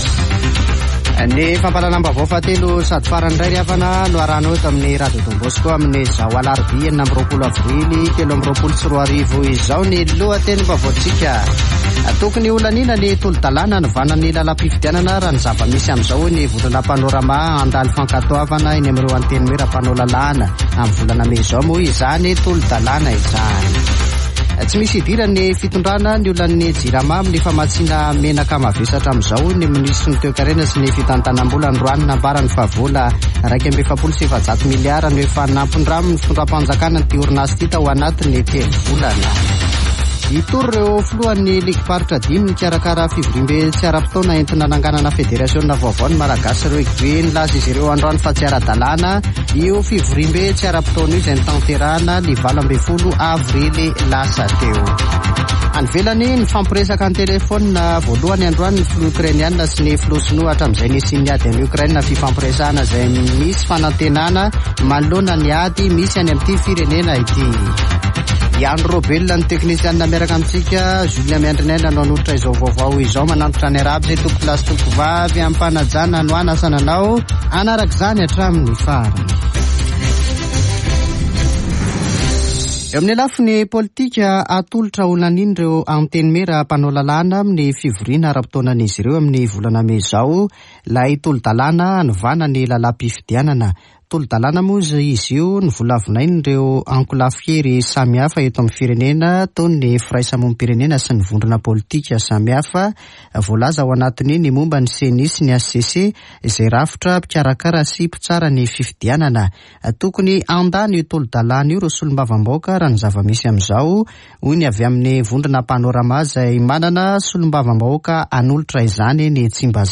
[Vaovao hariva] Alarobia 26 aprily 2023